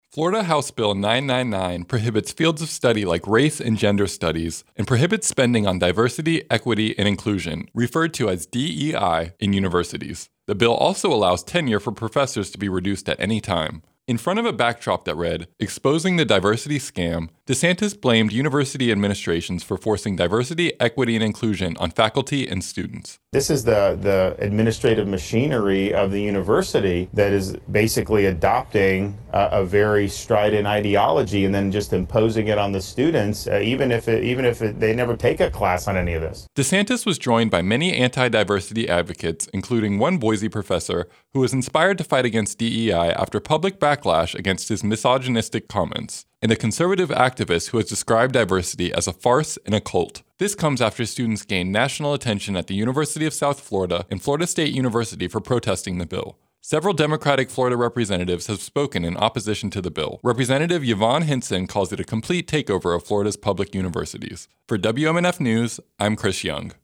Governor Ron DeSantis held a press conference in West Palm Beach as a bill that takes aim at diversity programs in universities is being heard in the legislature today.